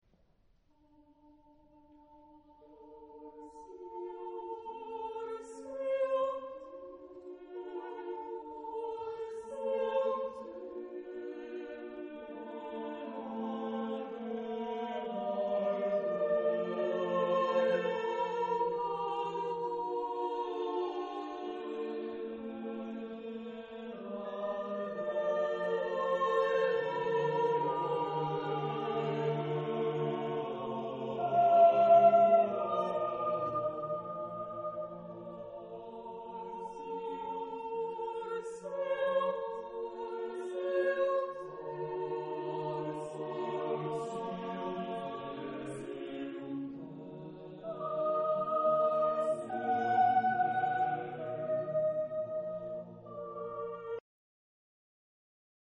Genre-Style-Form: Madrigal ; Secular
Type of Choir: SSATB  (5 mixed voices )
Tonality: dorian
Discographic ref. : Internationaler Kammerchor Wettbewerb Marktoberdorf